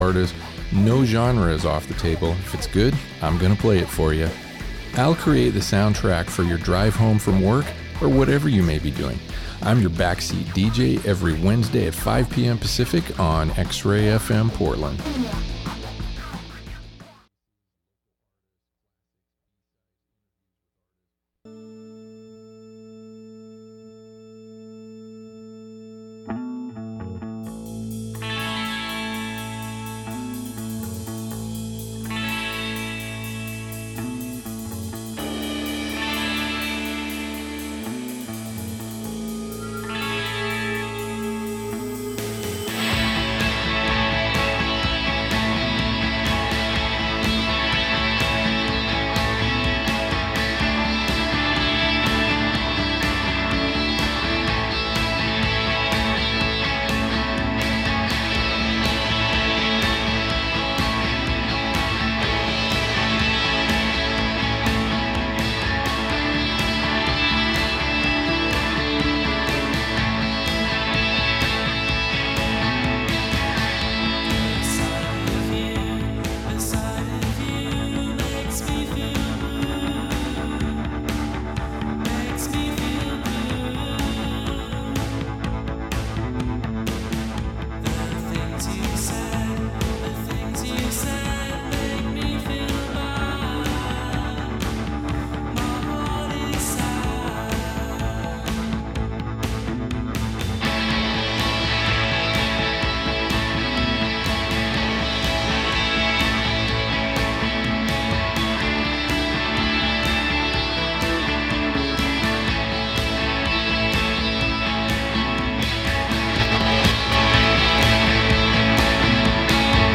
Weirdo screamers and trashy creepers.